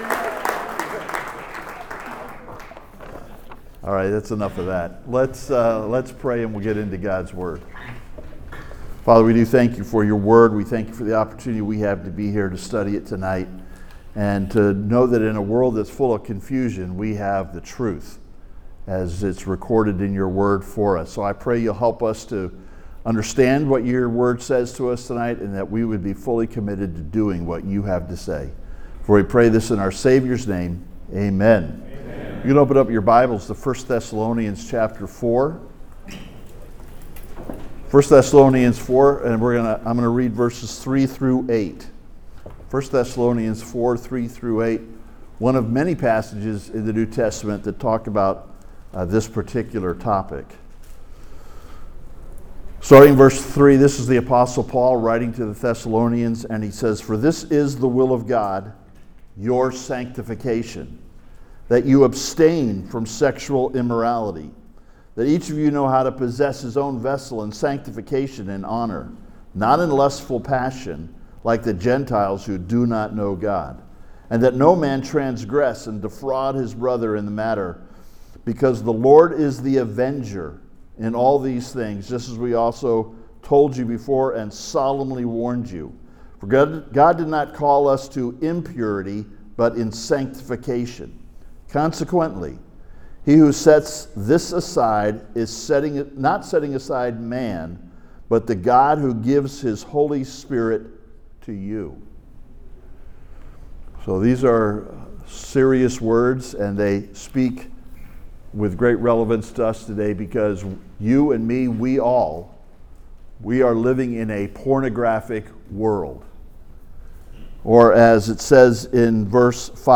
Living a Pure Life in a Pornographic World (Sermon) - Compass Bible Church Long Beach